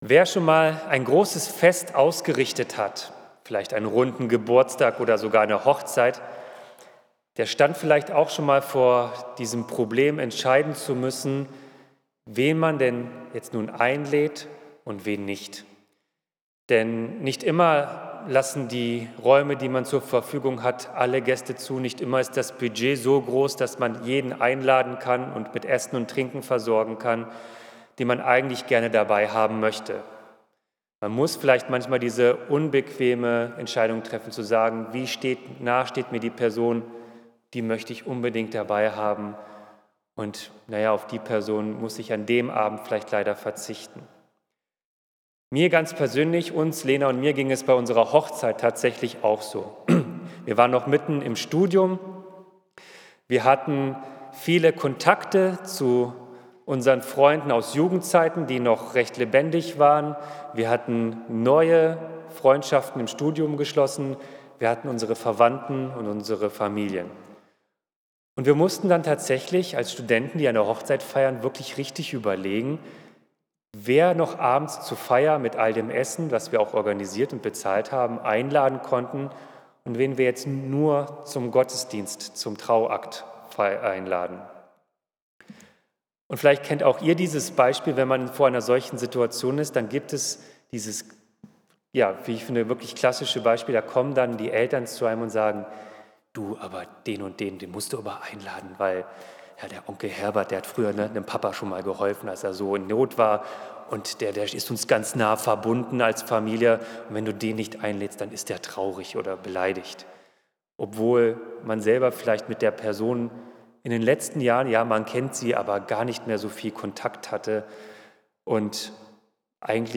Predigt zu 2 Korinther 9, 6-15 - Reichlich beschenkt - reichlich bedankt | Bethel-Gemeinde Berlin Friedrichshain